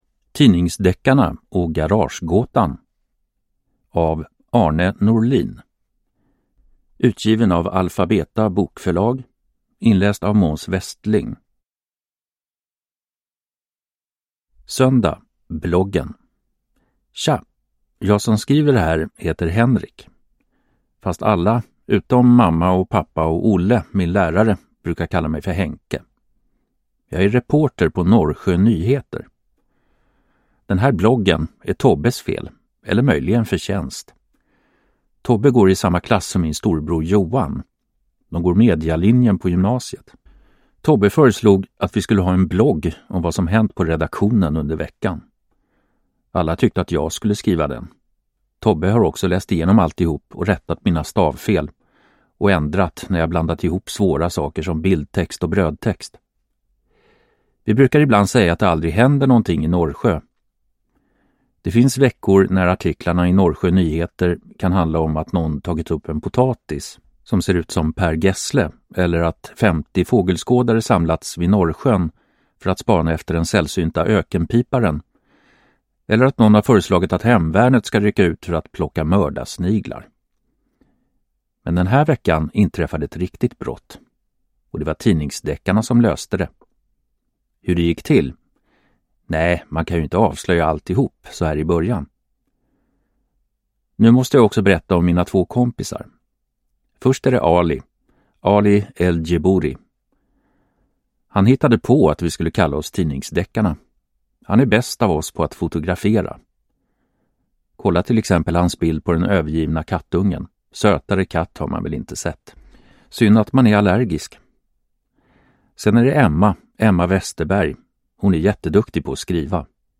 Tidningsdeckarna och garagegåtan – Ljudbok